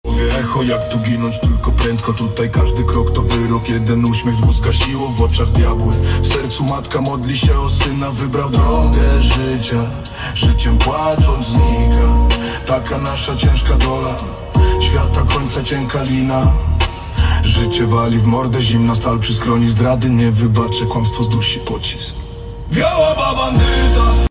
Szukam tytułu dwóch polskich piosenek rap, poniżej tekst (na dole tekst, a w załączeniu pliki dźwiękowe):